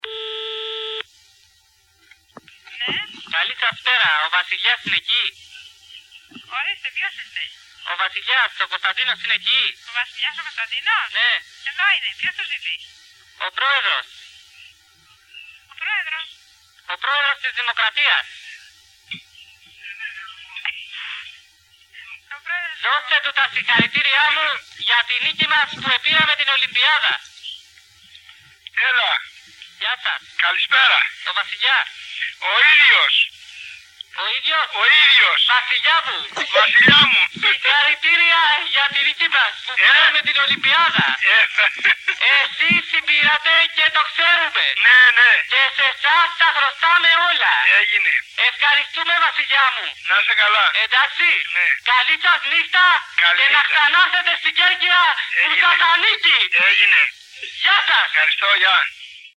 Σημείωση: Η κακή ποιότητα του ήχου δεν οφείλεται στα mp3.